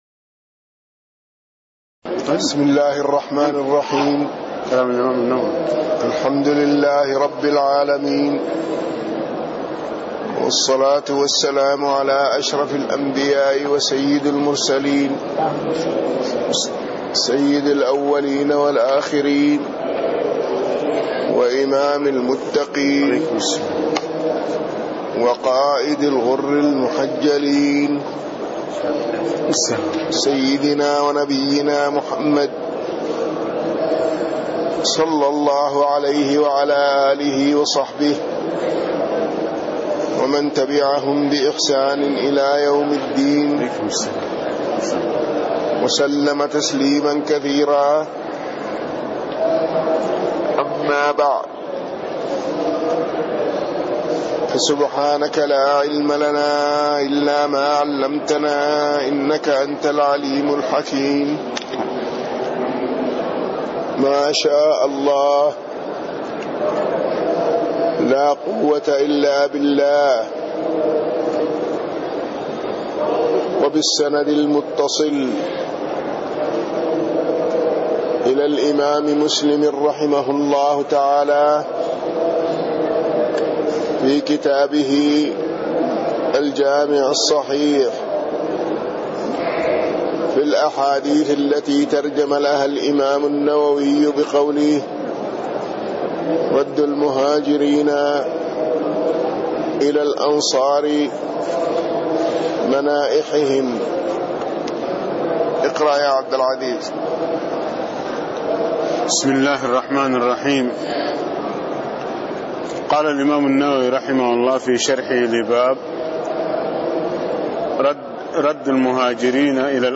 تاريخ النشر ٢١ شوال ١٤٣٥ هـ المكان: المسجد النبوي الشيخ